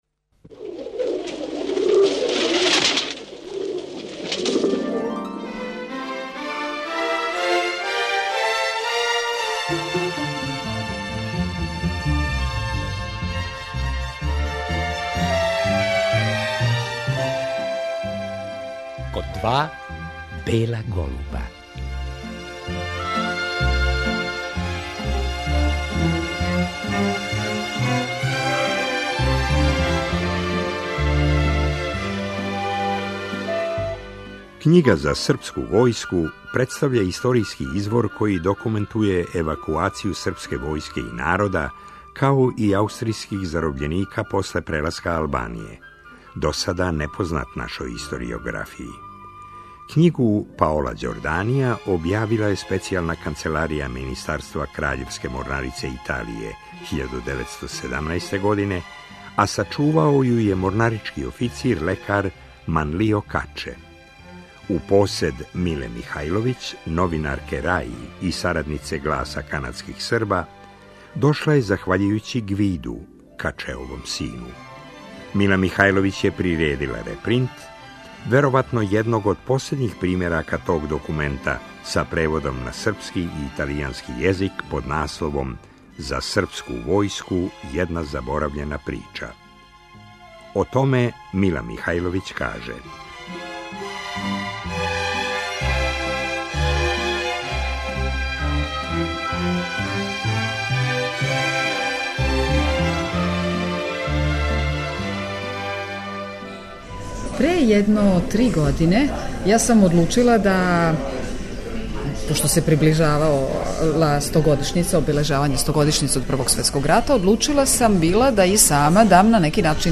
Снимак са тог излагања чућемо у вечерашњој емисији.